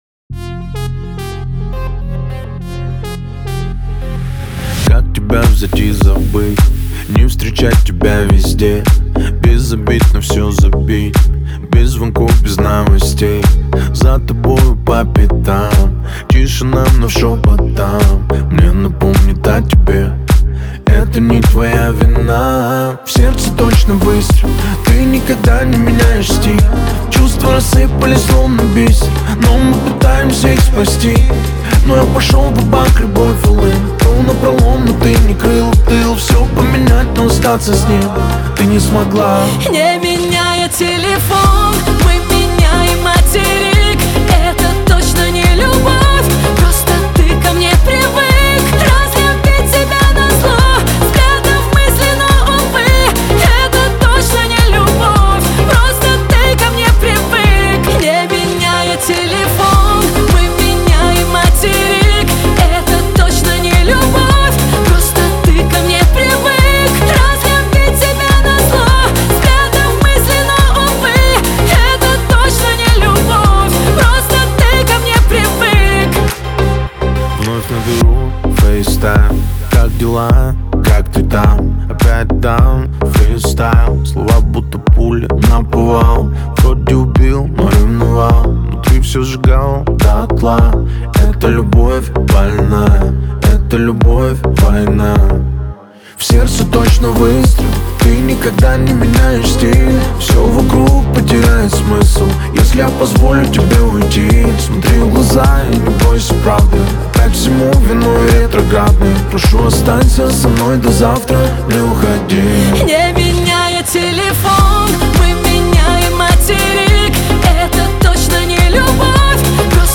Грустные